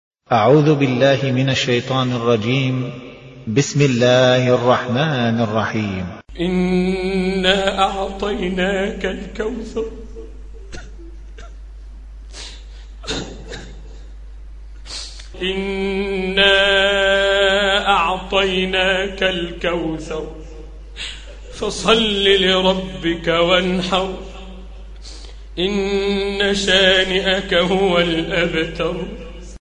Чтение Корана